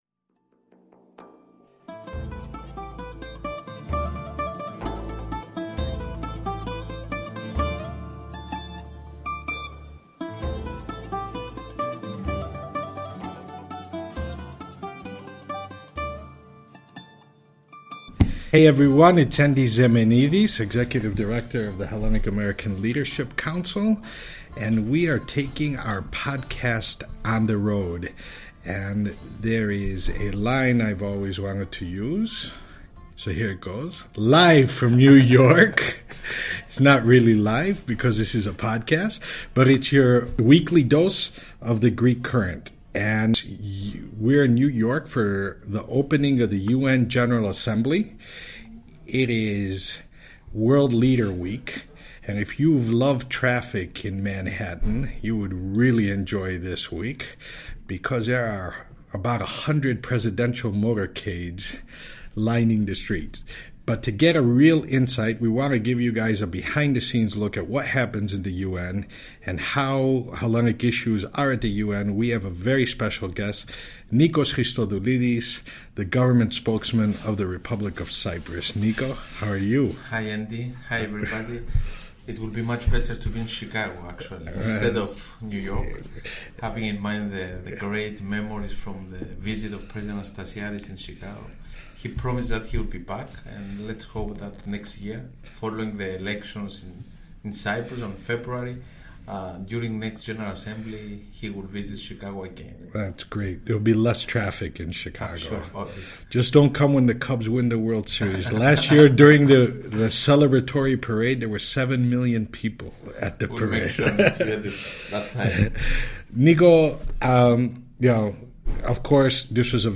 Live from New York. . .
This week, I got to give the listeners of The Greek Current a behind the scene’s look of President Trump’s and UN Secretary General Guterres’ first opening session through a discussion with Nikos Christodoulides — government spokesman of the Republic of Cyprus — and some first hand reporting on what occurred on the sidelines of the UN General Assembly.